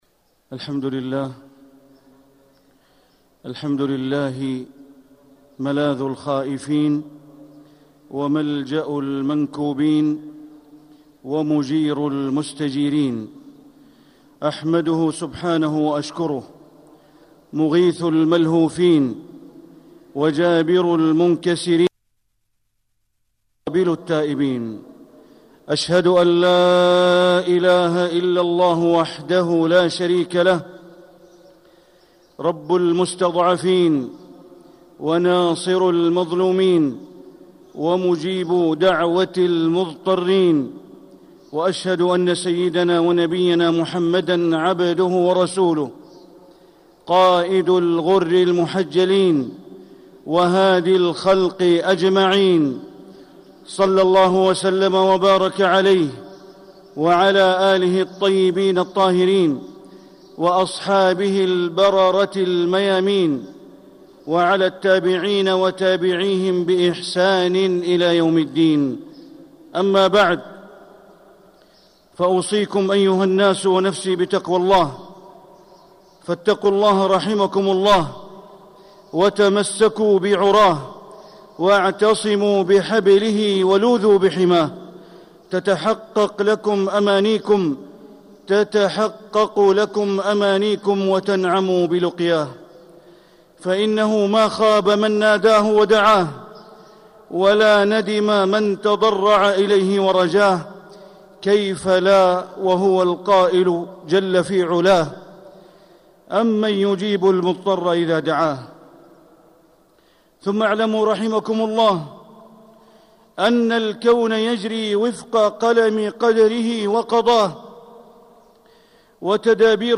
مكة: إحسان الظن بالله عز وجل - بندر بن عبد العزيز بليلة - طريق الإسلام
مكة: إحسان الظن بالله عز وجل - بندر بن عبد العزيز بليلة (صوت - جودة عالية